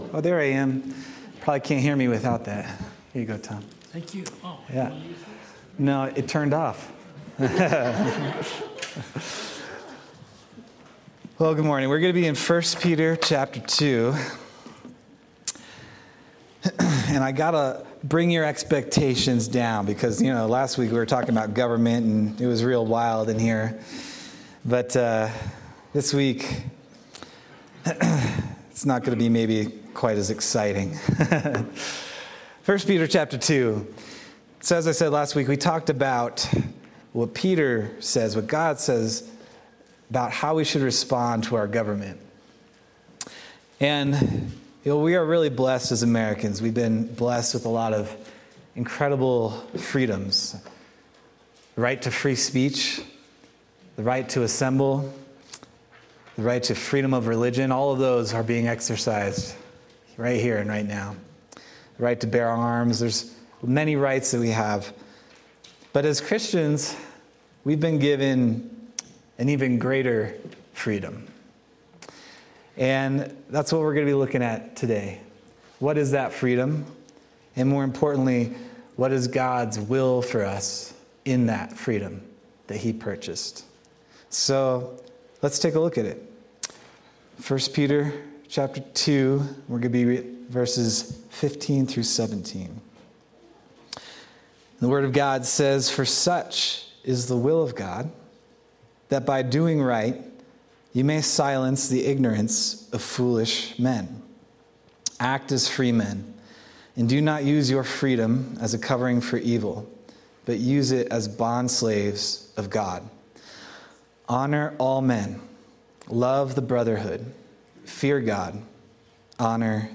March 9th, 2025 Sermon